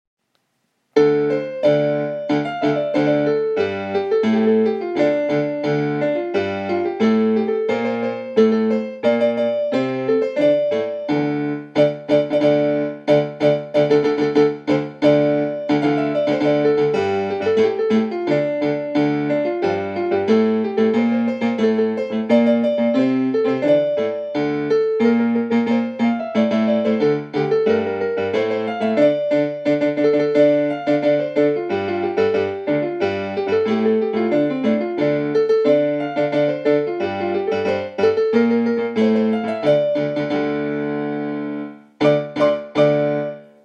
オリックスバファローズ #3 安達了一 応援歌 (改正版)